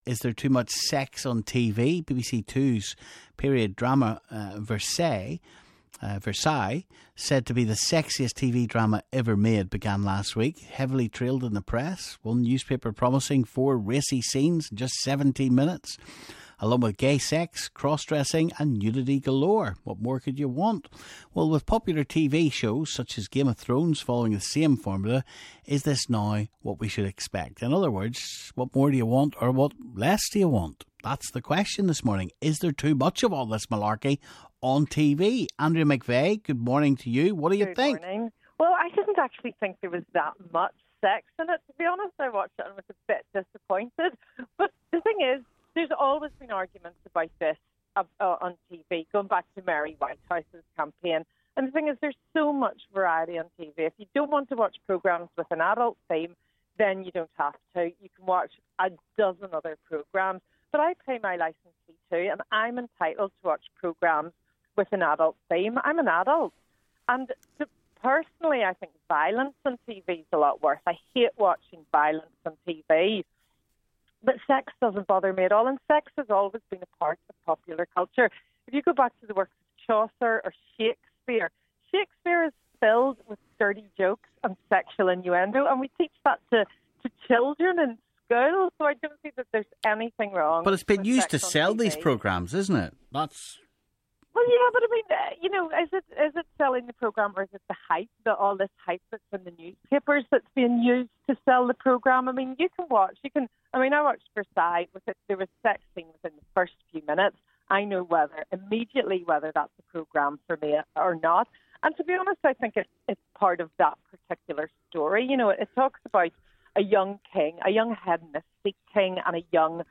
Or is there such a thing as too much sex on TV? Commentators